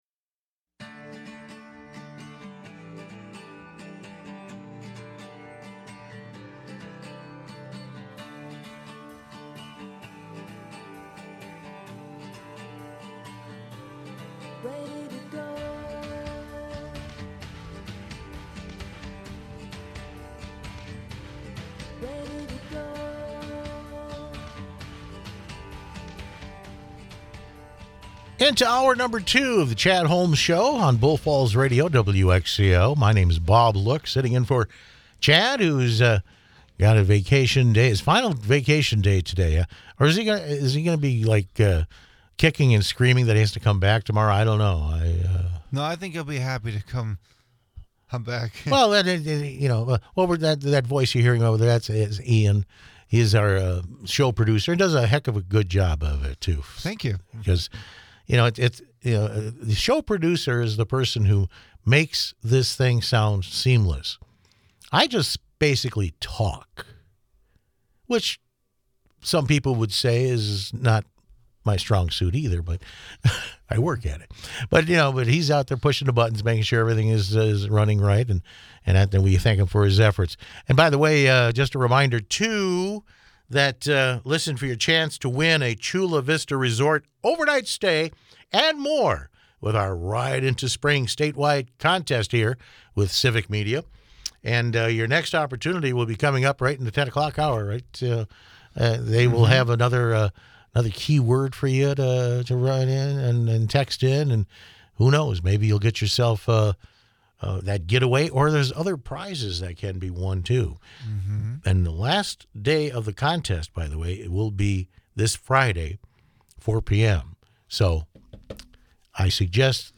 Also an interview